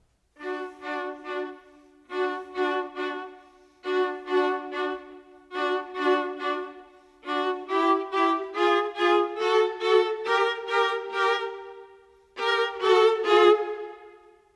Modern 26 Viola 01.wav